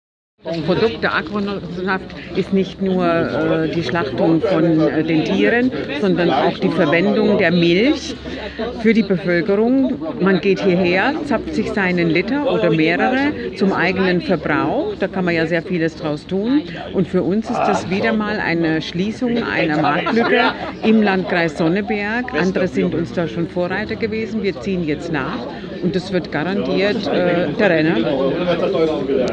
O-Ton: Landrätin von Sonneberg, Christine Zitzmann
o-ton-landraetin-sonneberg1.mp3